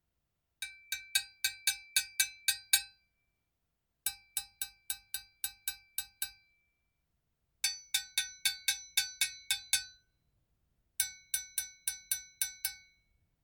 ギニア製 ドゥンドゥンベル
リング/スティック~裏リング/スティック